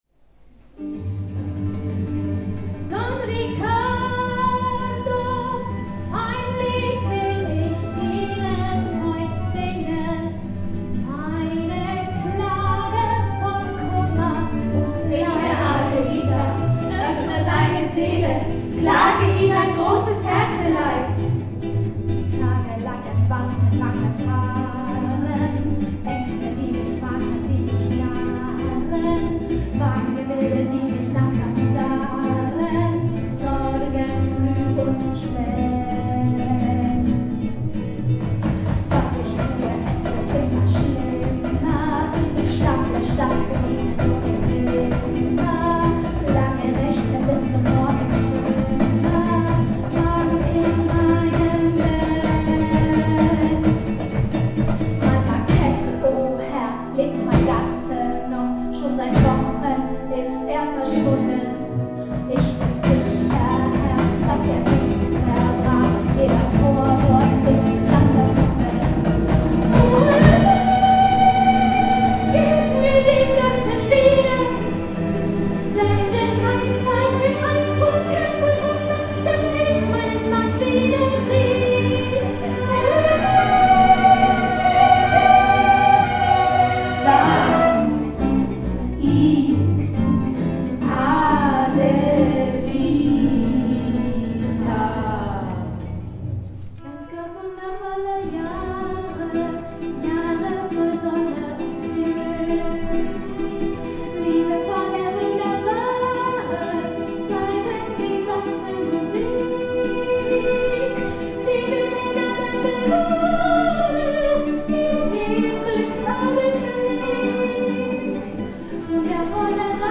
Bevor der Vikar aber seinen Segen erteilen kann, unterbricht Adelita mit ihrem Lied, in dem sie, unterstützt von den Wäscherinnen, ihr Leid klagt: